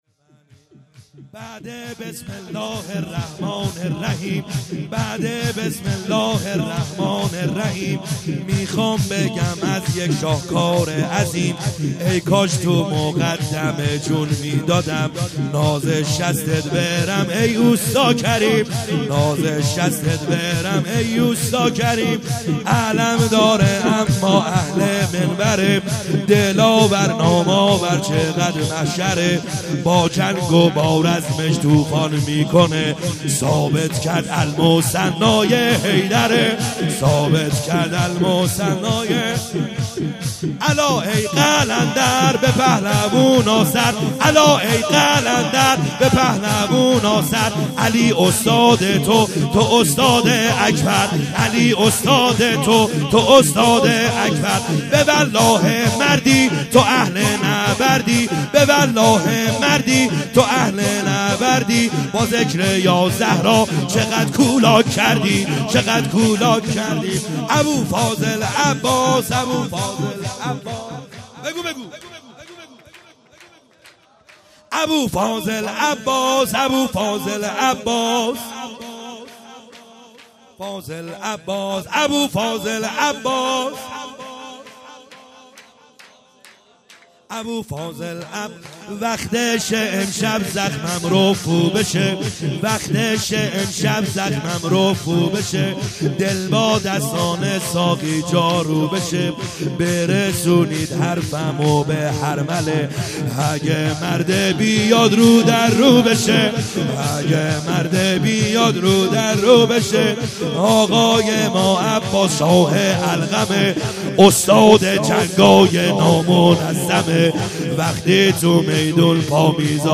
خیمه گاه - بیرق معظم محبین حضرت صاحب الزمان(عج) - سرود | بعد بسم الله الرحمن الرحیم